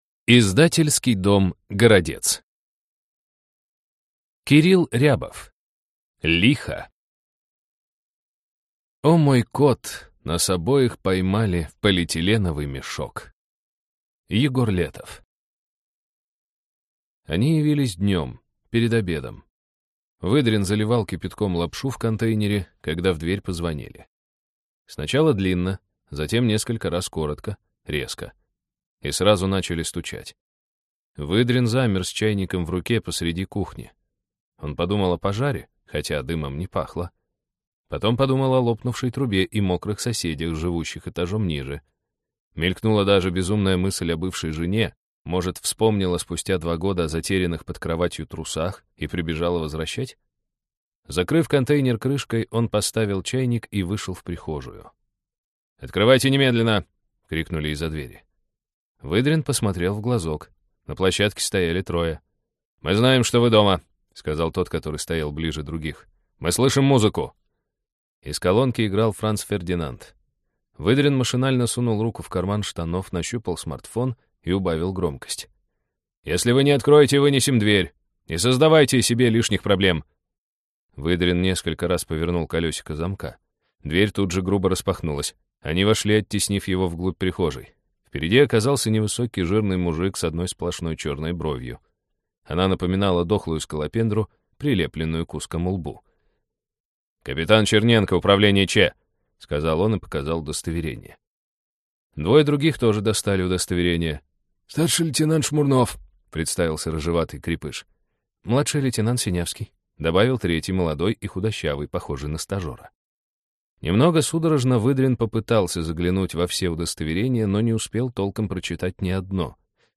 Аудиокнига Лихо | Библиотека аудиокниг